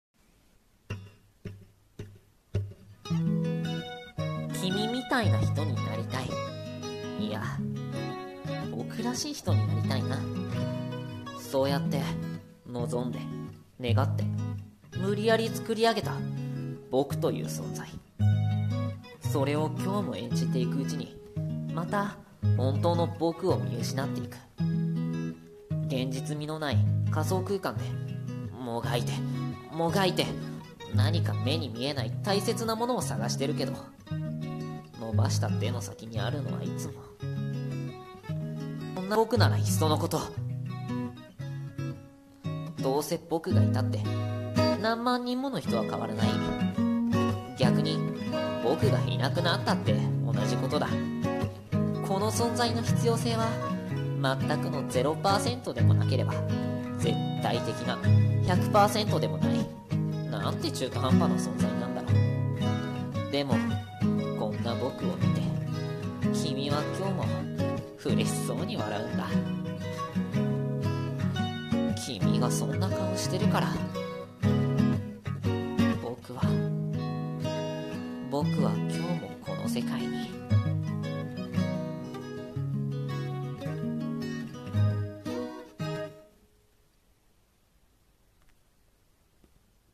【朗読声劇】自傷無色